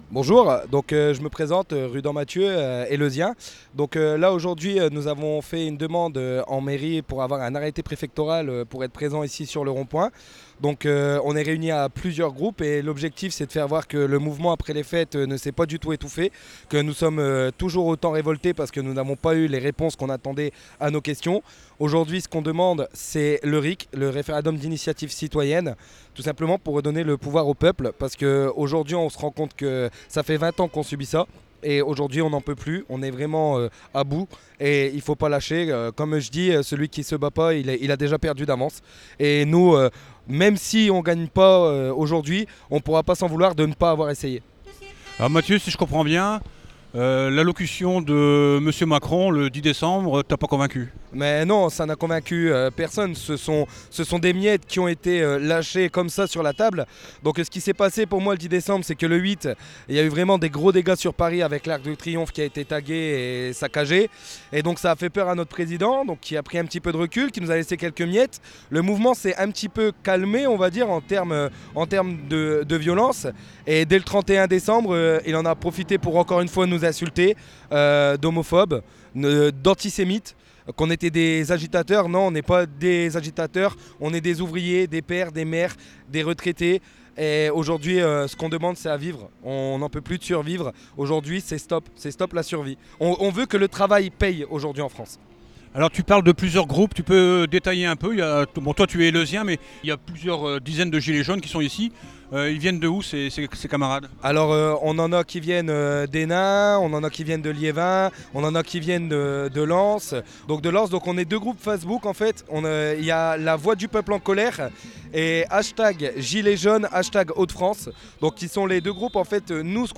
Micros rebelles reportage
Bien déterminés à montrer qu’ils ne baissent pas les bras et que les annonces gouvernementales aussi bien que la stigmatisation du mouvement dans les médias dominants n’entament pas leur volonté de changement politique et social, différents groupes de gilets jaunes réunis se sont installés depuis cette semaine au rond-point d’Eleu-dit-Leauwette.